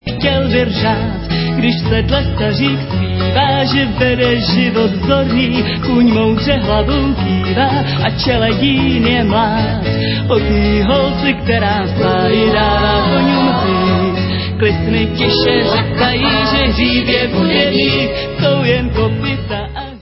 Renesančně laděnými autorskými písněmi